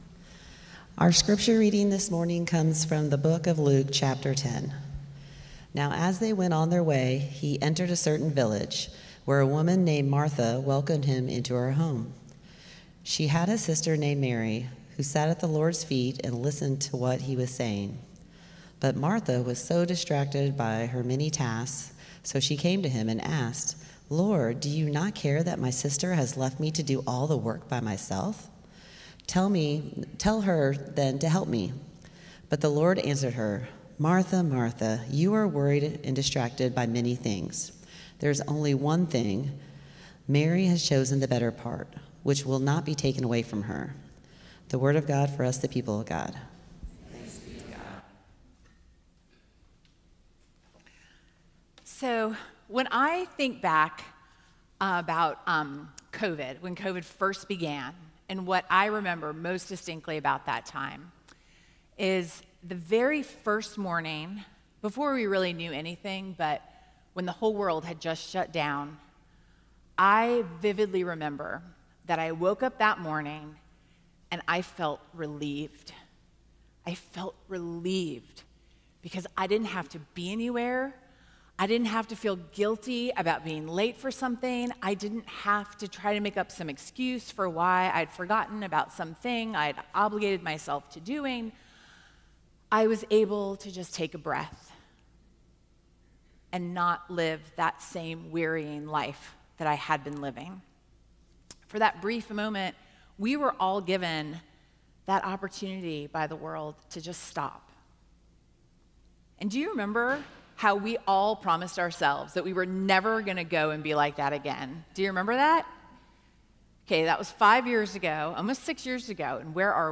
First Cary UMC's First on Chatham Sermon &ndash